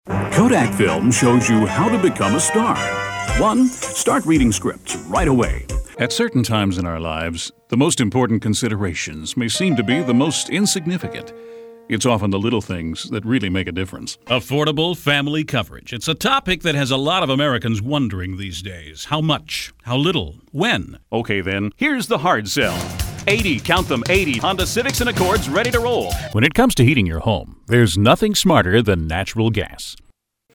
Male Voice Over Talent